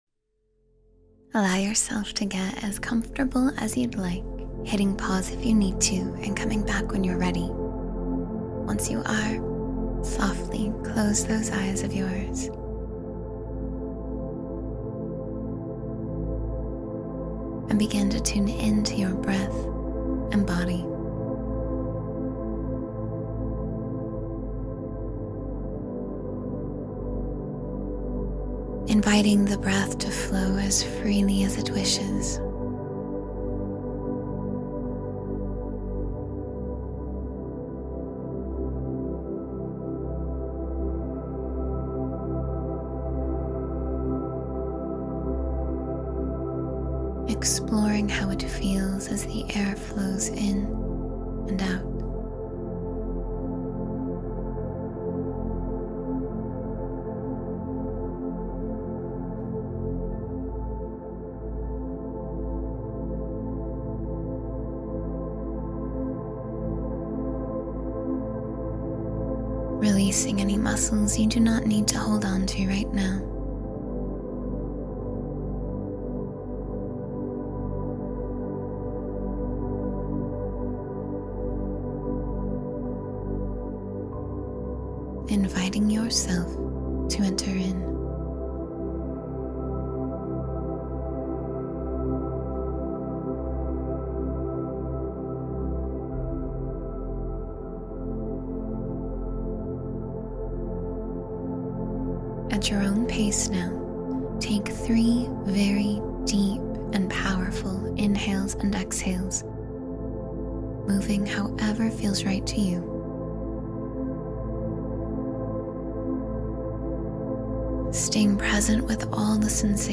Becoming Space — A Deep Meditation